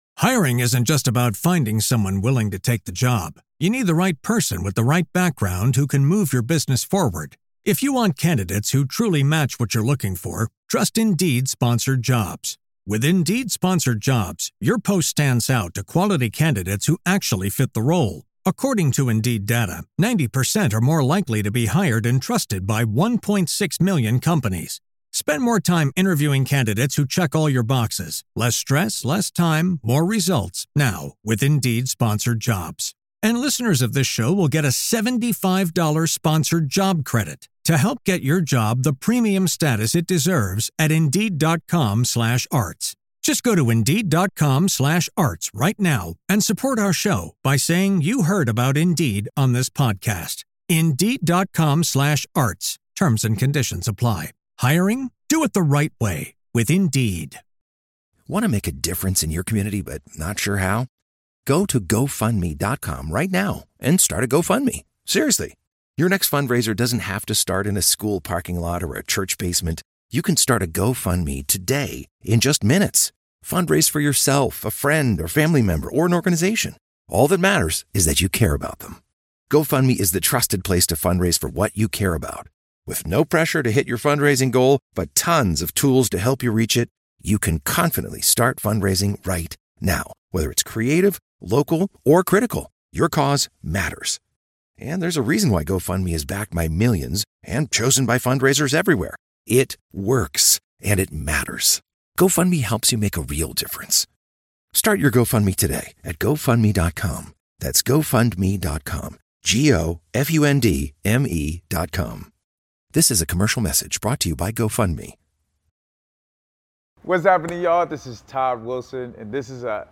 In this episode we discuss the honest opinions about AAU, high school basketball, and parenting! This is an open discussion, we definitely want all of you commenting with any questions or topics you would like to hear covered!